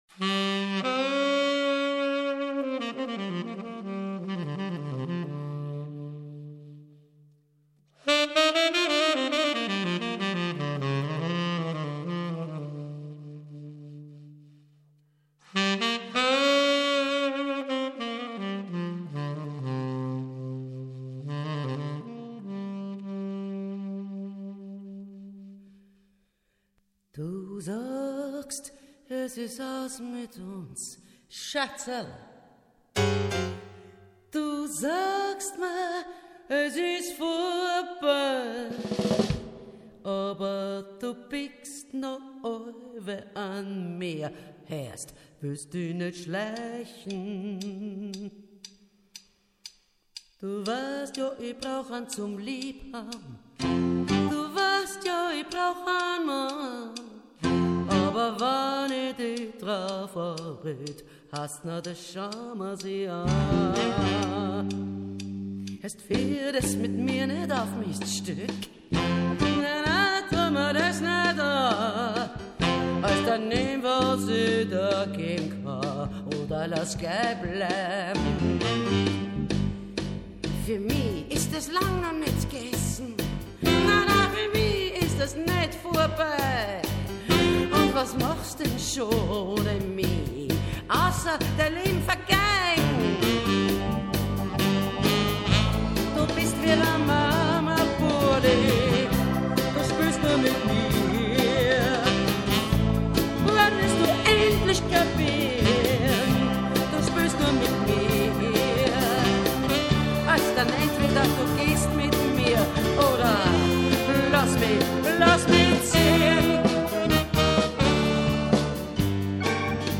Eine Demo-CD für ein Live-Projekt
Gesang, Autoharp, Bass
Gitarre, Gesang
Keyboard, Klavier, Tenorsaxophon, Akkordeon, Gesang
Bass, Saxophone
Schlagzeug
E-Gitarre, Gesang
Saxophone, Querflöte, Mundharmonika, Gesang